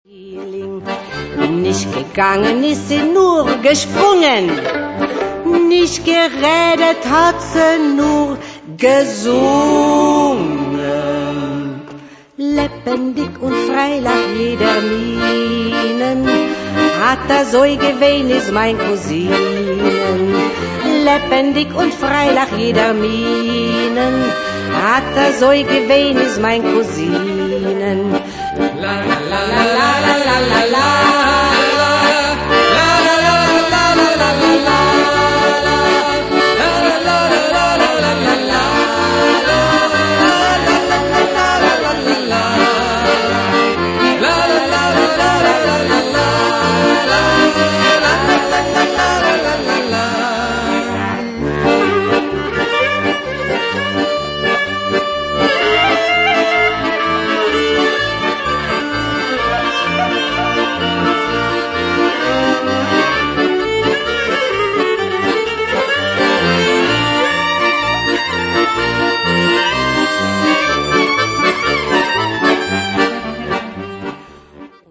Music of Eastern Europe: Klezmer, Romany and ...
violin